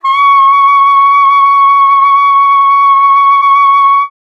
42e-sax13-c#6.wav